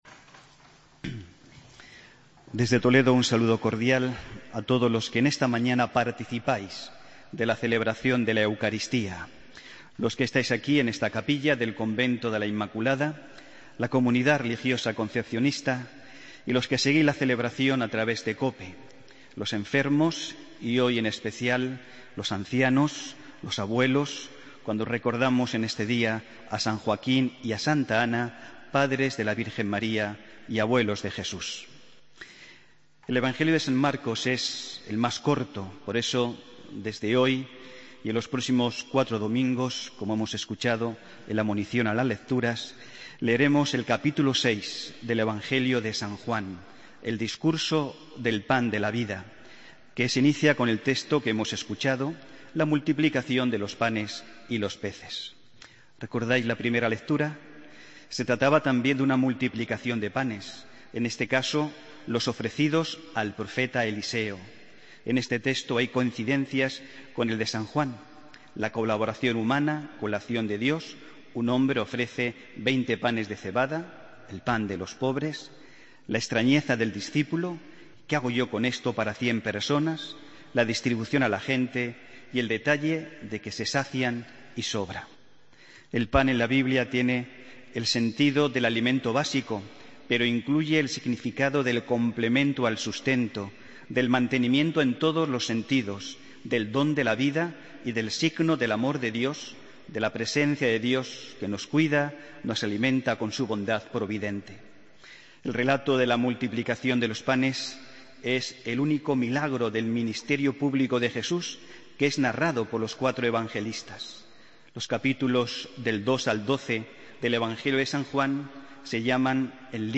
Homilía del domingo 26 de julio de 2015